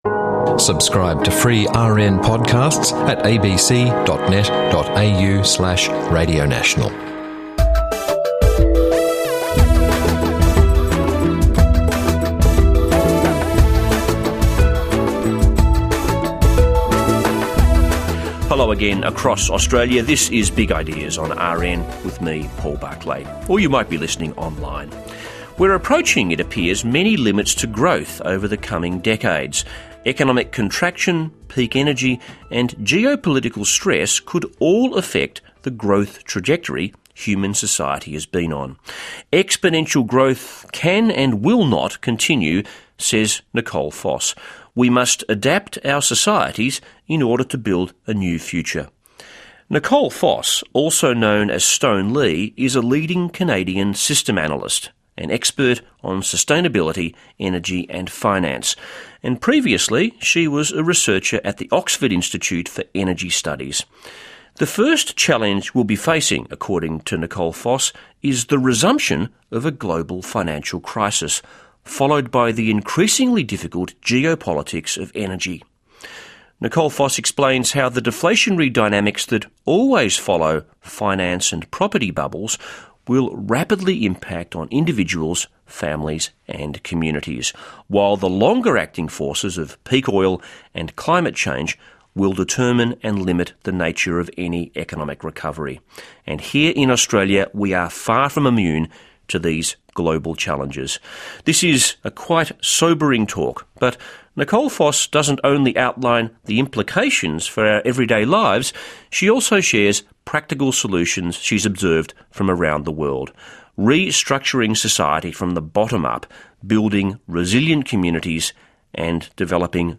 This talk offers a profound understanding from the systems perspective of the current realities of finance and economics, and the serious predicament we are in. Here is the full audio recording of the talk, originally posted here, and it might be all that some readers need in order to absorb the excellent analysis in full: